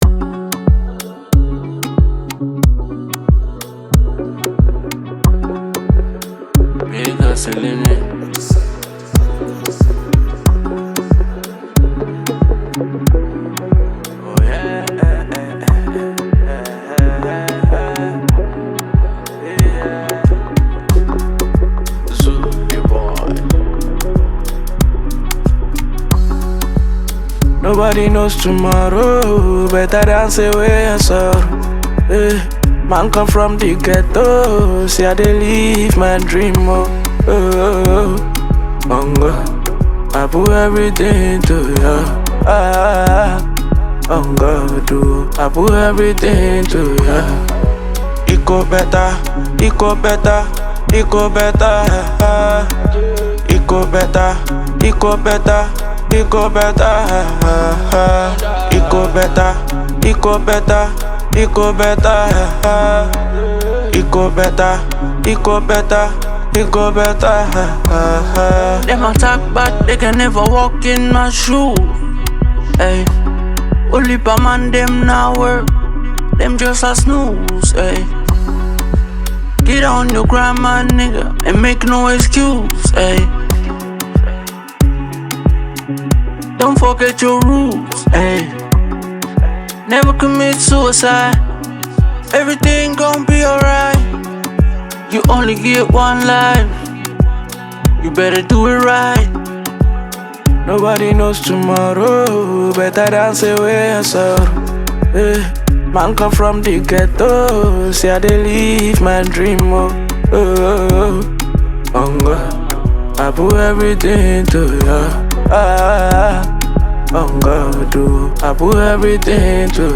blends Afrobeat with contemporary sounds
With its upbeat tempo and catchy sounds